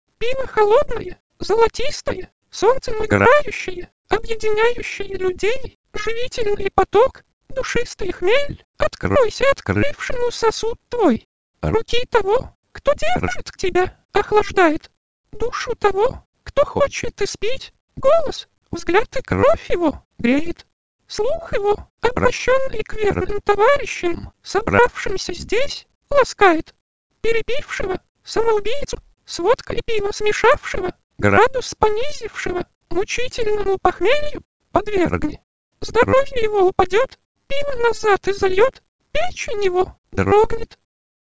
А что это за хрипы там проскакивают?
И еще вот это, поется на тот же мотив: